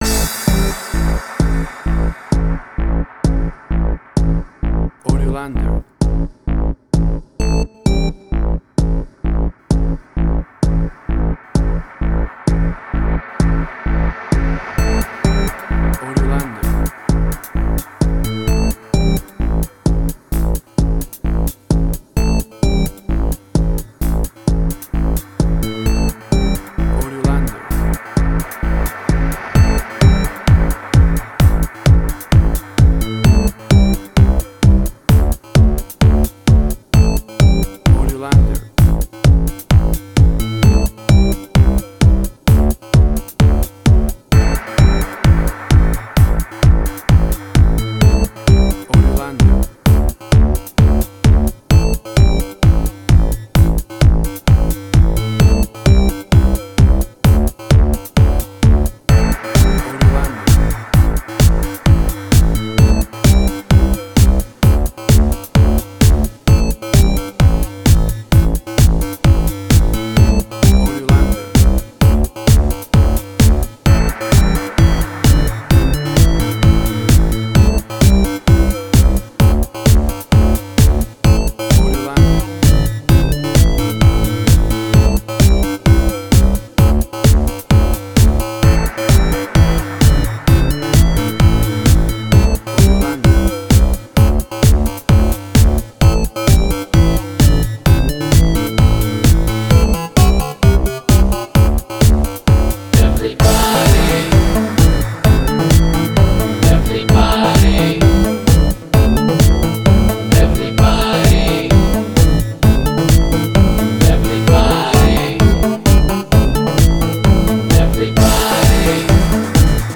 Jewish Techno Trance
Tempo (BPM): 131